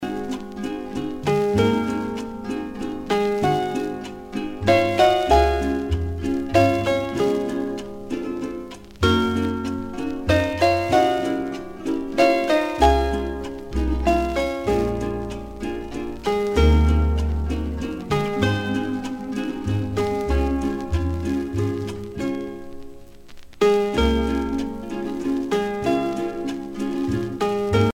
danse : valse lente
Pièce musicale éditée